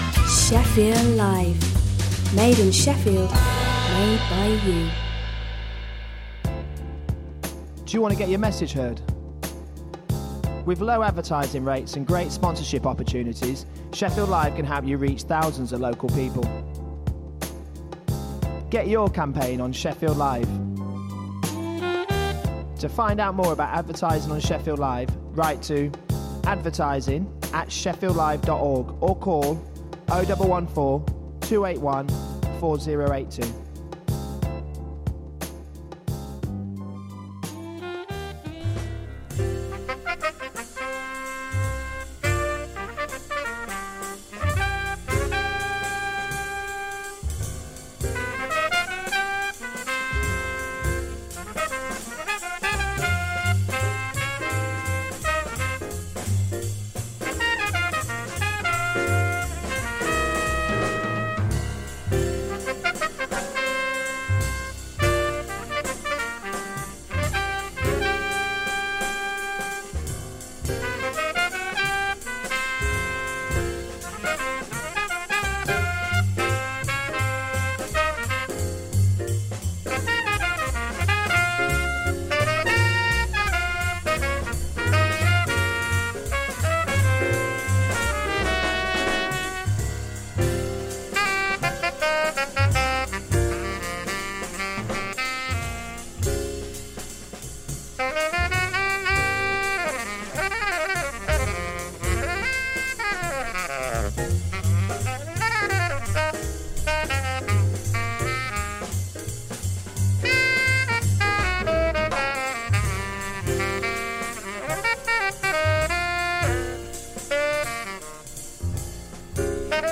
An extensive weekly exploration of modern jazz; be-bop, hard bop, West Coast, Latin, bossa nova, vocals, fusion and soul.